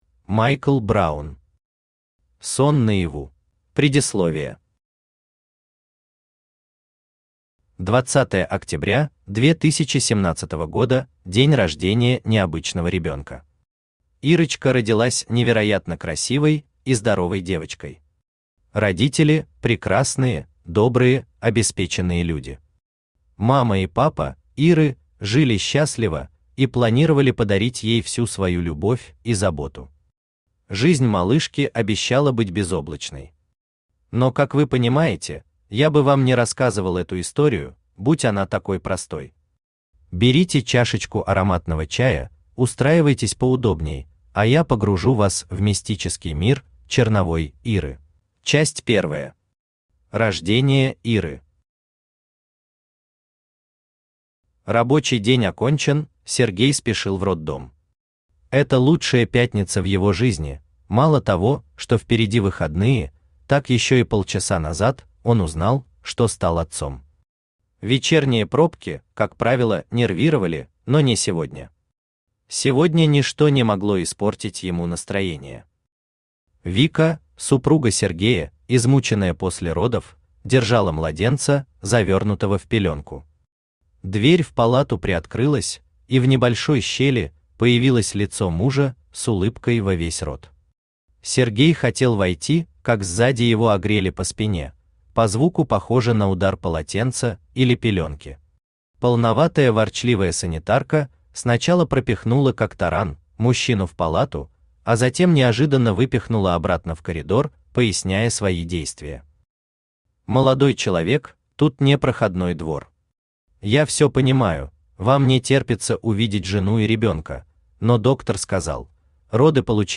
Аудиокнига Сон наяву | Библиотека аудиокниг
Aудиокнига Сон наяву Автор Майкл Бобби Браун Читает аудиокнигу Авточтец ЛитРес.